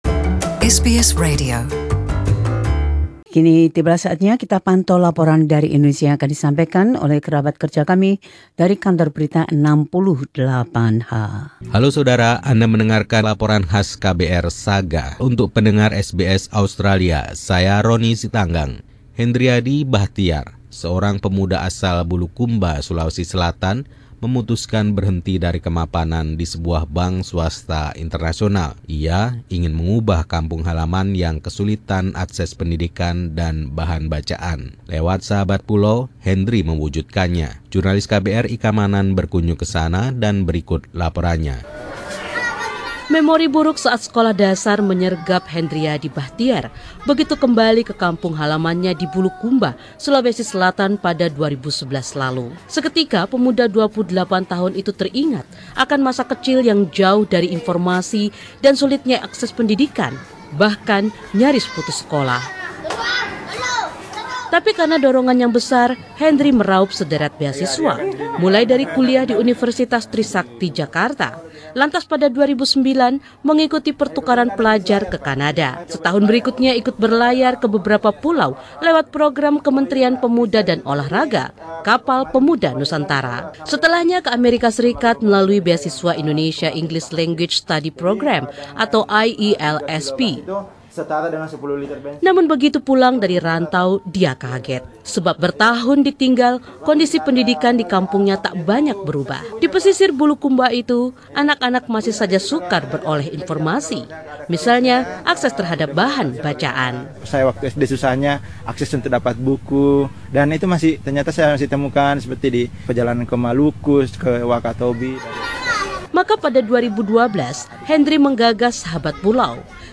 Laporan khas KBR 68H ini menceritakan upaya seorang pemuda yang terinspirasi untuk menjalani kehidupan yang akan bermanfaat bagi orang lain.